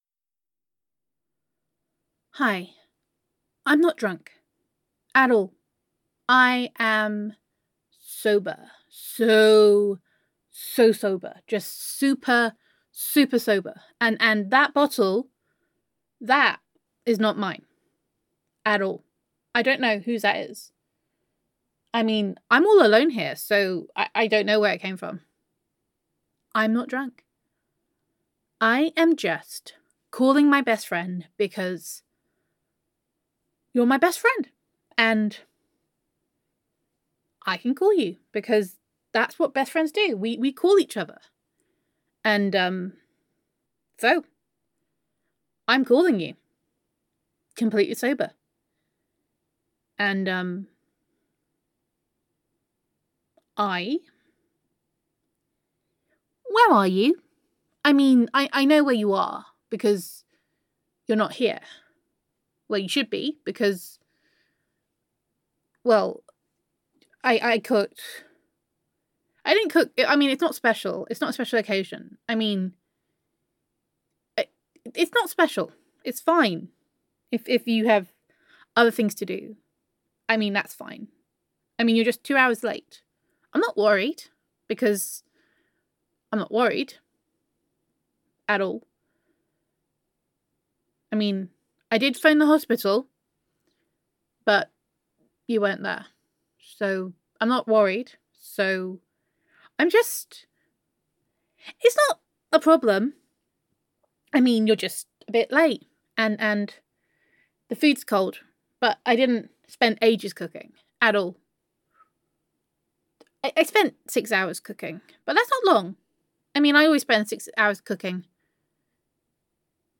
[F4A] Where Are You? [I Am Not Drunk][Not at All][I Am Sober][That Bottle Is Not Mine][Jealousy][You Are Late][Voicemail][Gender Neutral][Drunken Best Friend Love Confession]